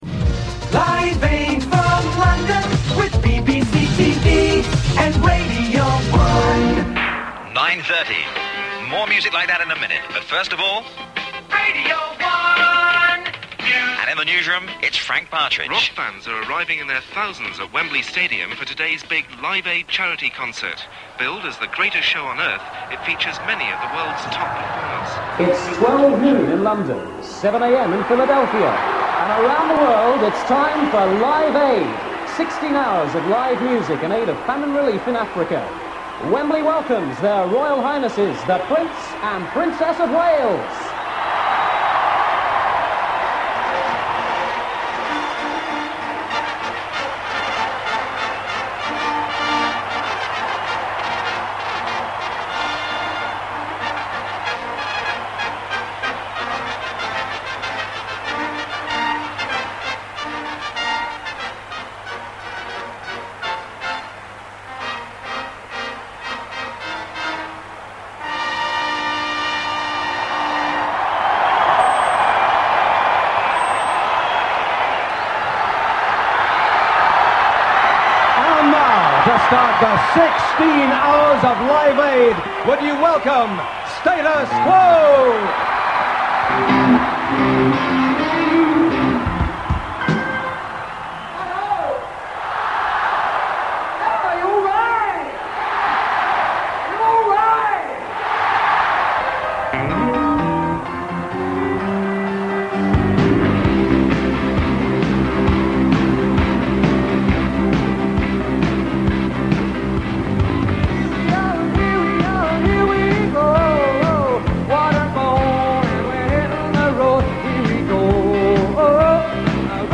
Major rock and pop acts had been encouraged and cajoled by Bob Geldof to perform in the 15-hour concert from London and Philadelphia. Here are some of the highlights from that memorable day.